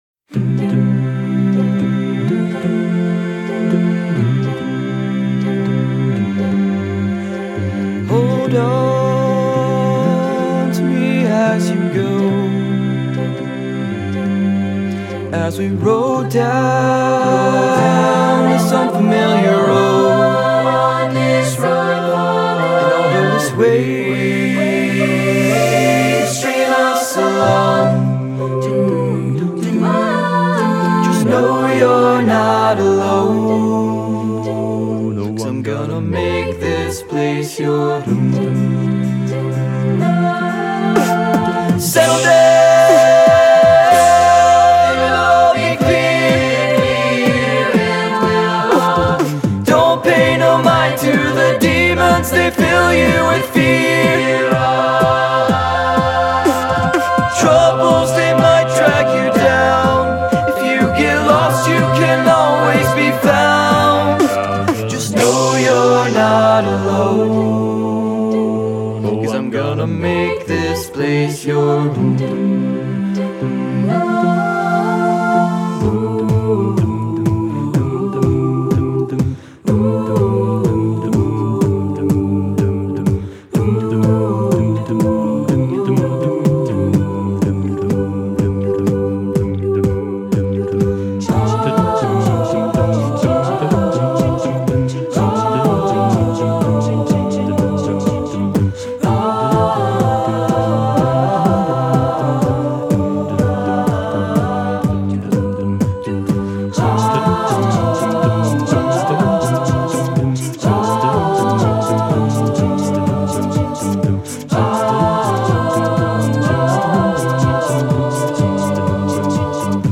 choral
SATB, sample